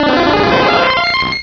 pokeemerald / sound / direct_sound_samples / cries / parasect.aif